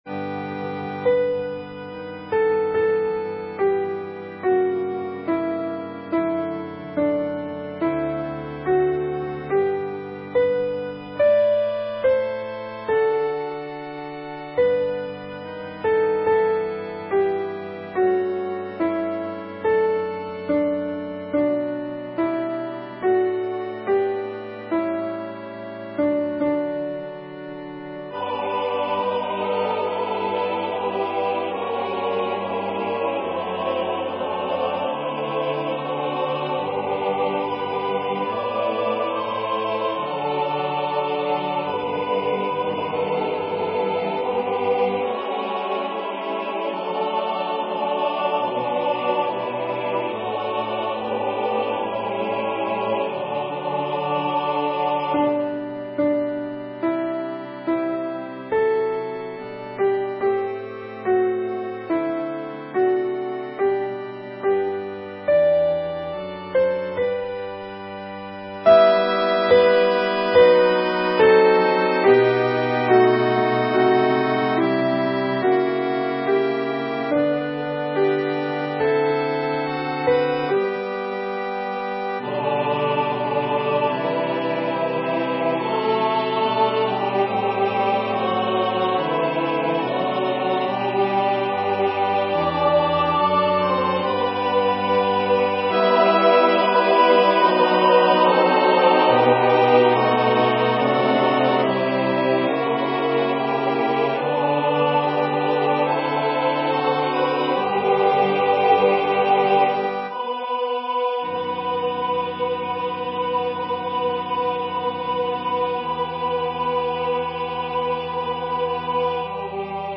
MP3 Practice Files: Child Chorus:
Number of voices: 4vv   Voicing: SATB, with Soprano solo
Genre: SacredMotetEucharistic song
Instruments: Organ